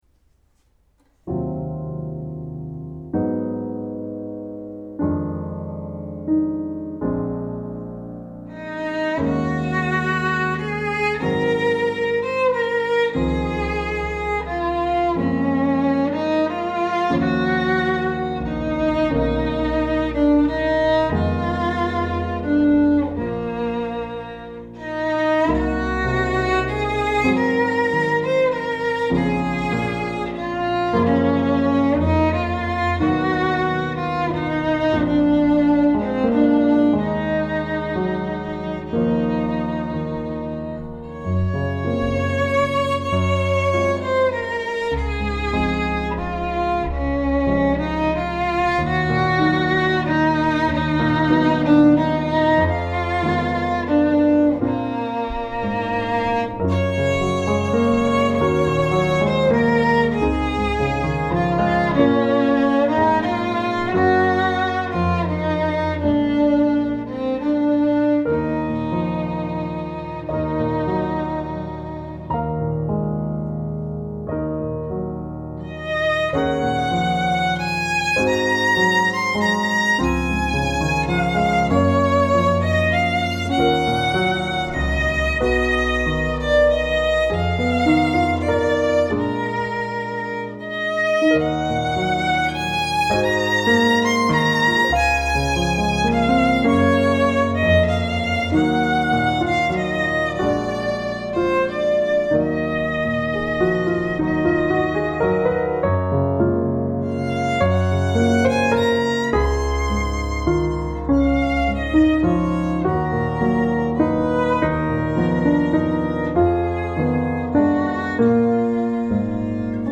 Violin and piano sheet music.
Key of am/F.